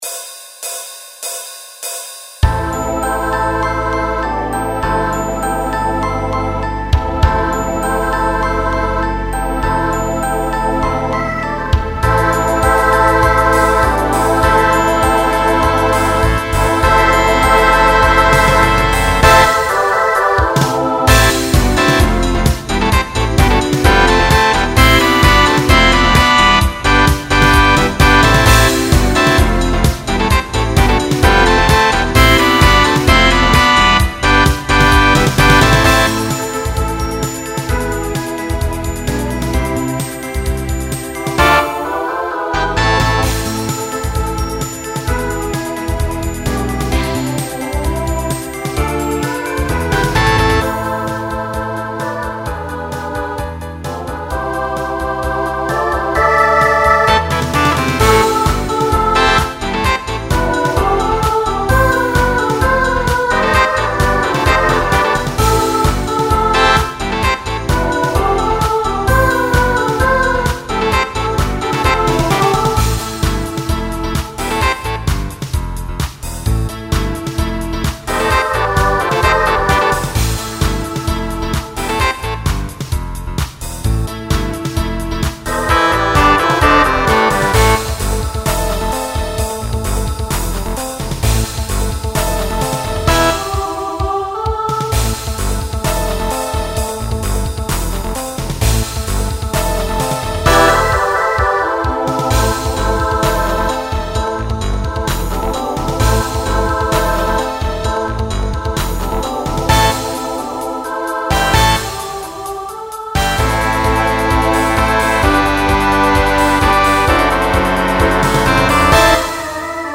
Genre Pop/Dance Instrumental combo
Voicing Mixed